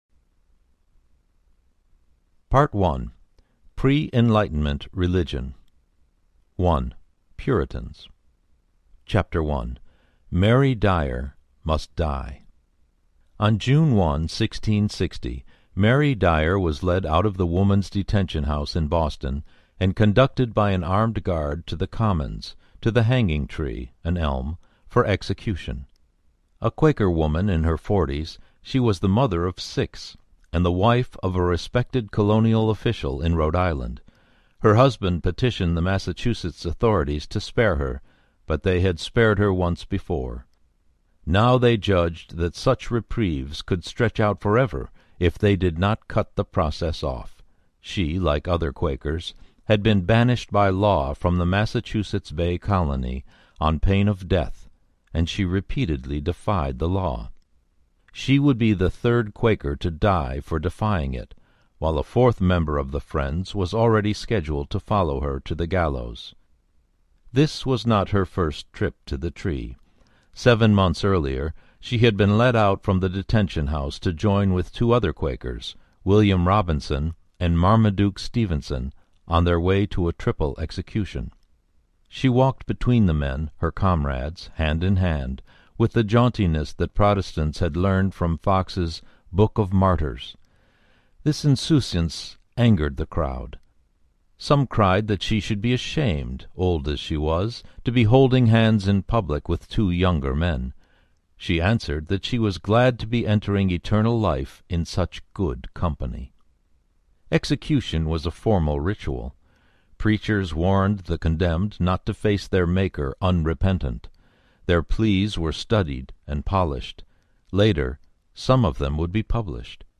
Narrator
20.0 Hrs. – Unabridged